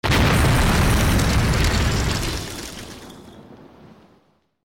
OtherDestroyed2.wav